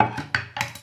default_use_workbench.ogg